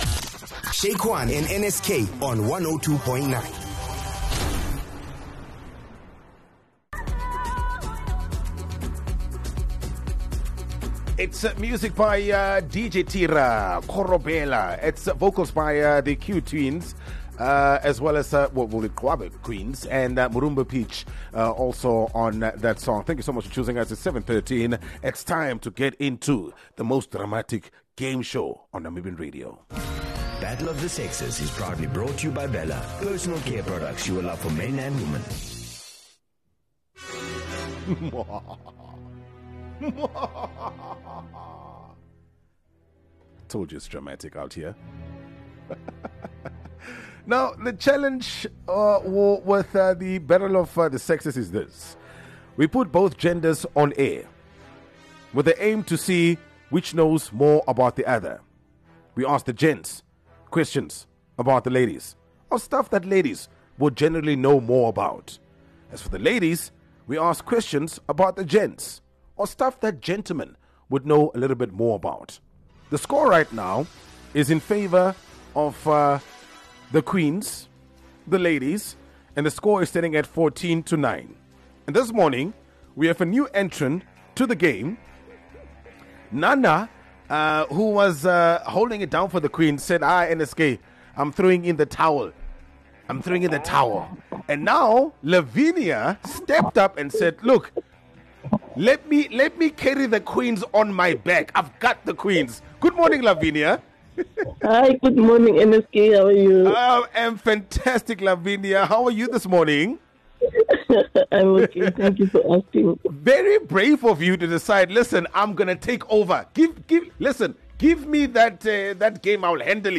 Battle of the Sexes is probably the most dramatic game show on Namibian radio. This is the bit where we have both genders on air with the aim to see which knows more about the other. So we will ask the gents questions about the ladies and ladies…we will ask questions about the gents!